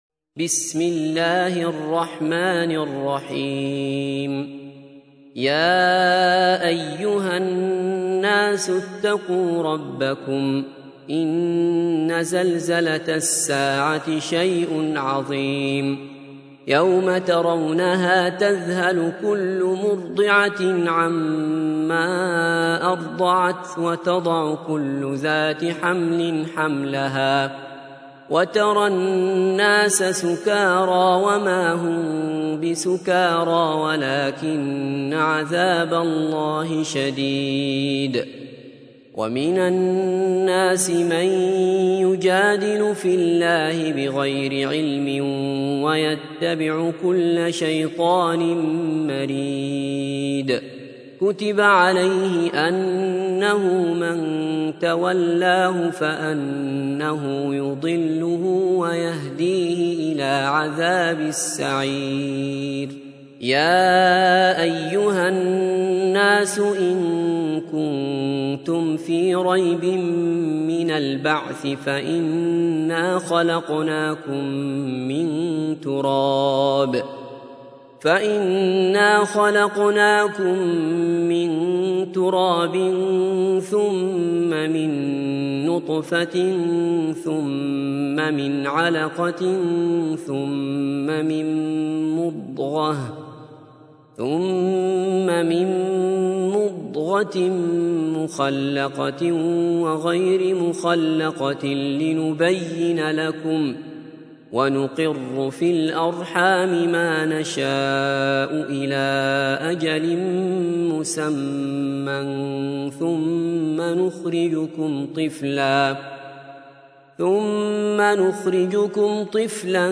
تحميل : 22. سورة الحج / القارئ عبد الله بصفر / القرآن الكريم / موقع يا حسين